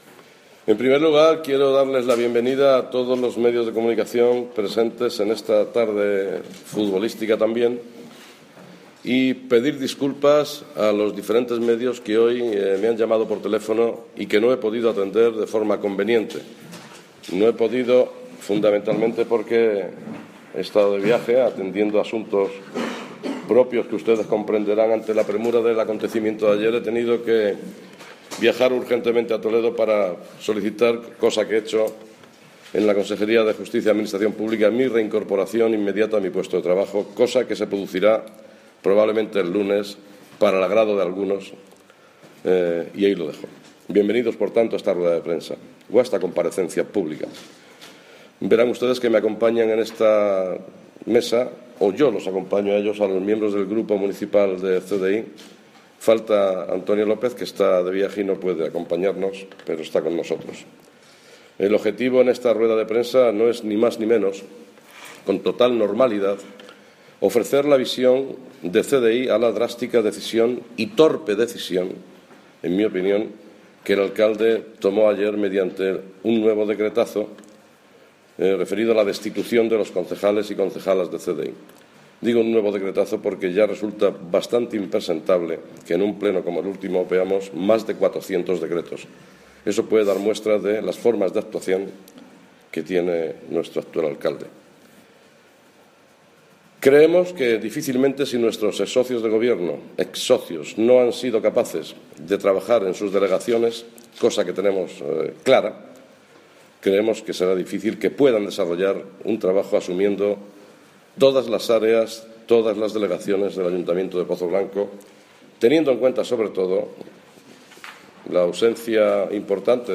Rueda de prensa del CDeI tras su cese del equipo de gobierno de Pozoblanco